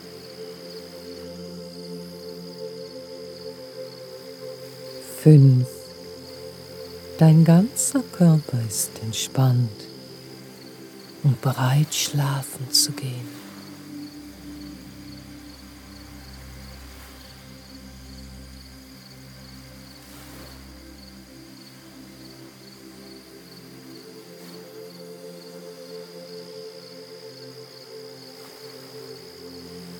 Die professionelle und sanfte sprachliche Führung hilft Ihnen dabei, in eine absolute körperliche Entspannung zu gelangen und Ihre Gedanken und Gefühle bestmöglich zu beruhigen.
Untermalt von fein abgestimmter, beruhigender Musik, sanften Naturklängen und sphärischen Melodien werden Sie währenddessen förmlich in den erholsamen Tiefschlaf getragen.
Für einen optimalen Effekt empfehlen wir das Hören über Kopfhörer.